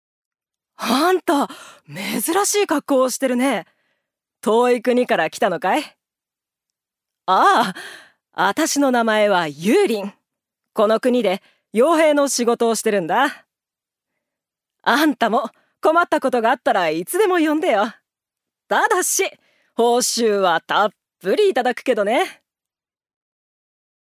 女性タレント
セリフ１